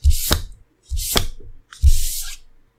eventCard.ogg